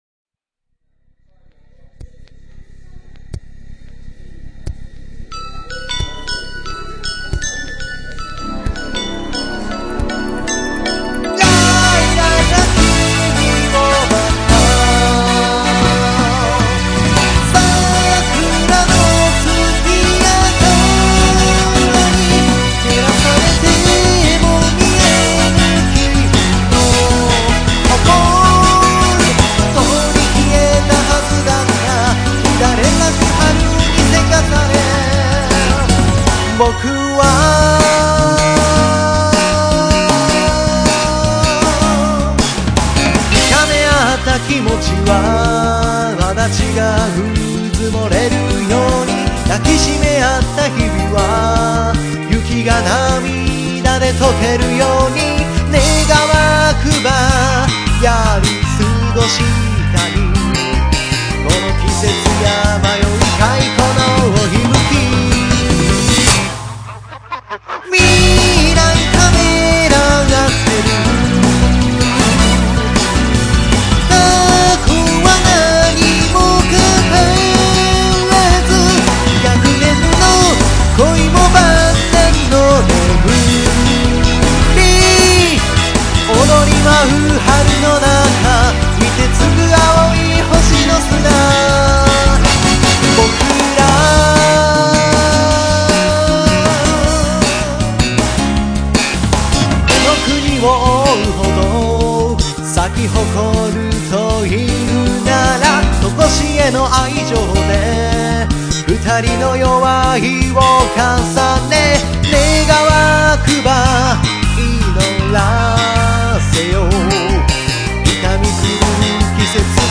まさに打ち込みとサックスの融合といった感じ。